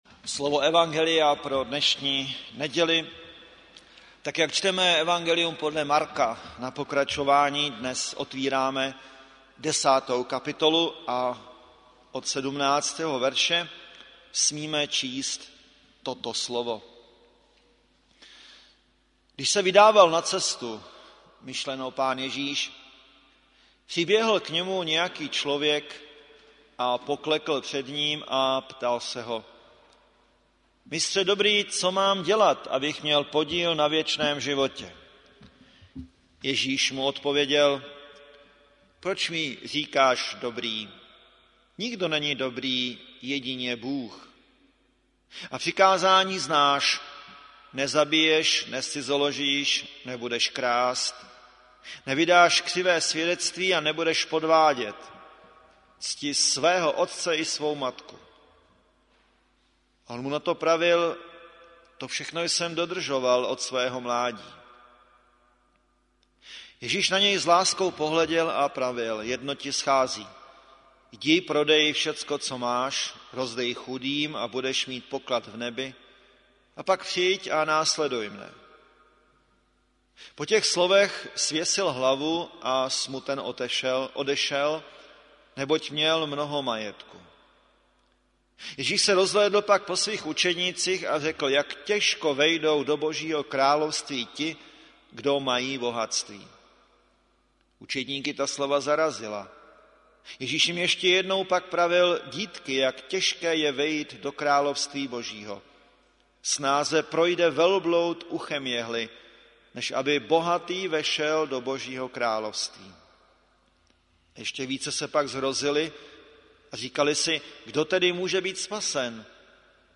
Záznam kázání z bohoslužeb.